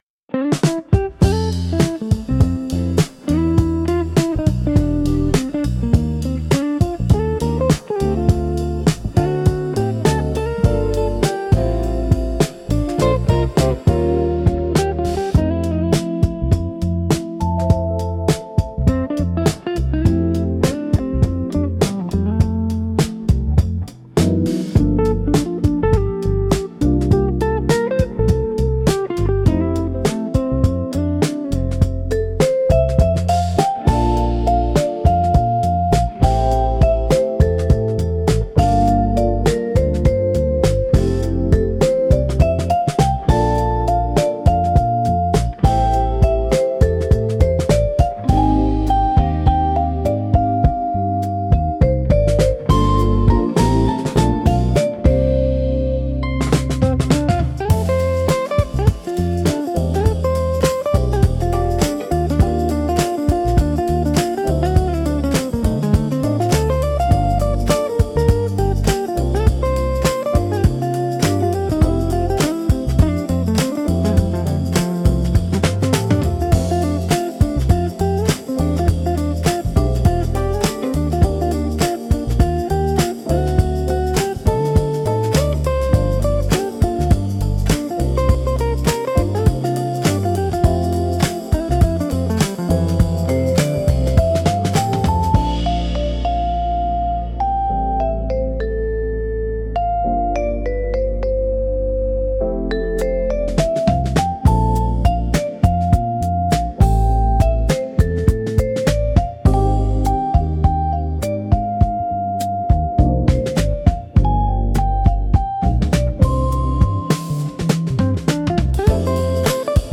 素朴で優しいギターの旋律が、忙しい日常をふと忘れさせ、歩幅を少し緩めたくなるような安心感を与えてくれます。
• ジャンル： アコースティック / フォーク / イージーリスニング / ヒーリング
• 雰囲気： 懐かしい / 温かい / 優しい / 穏やか / 素朴
• テンポ（BPM）： ミドル（歩くような速さ）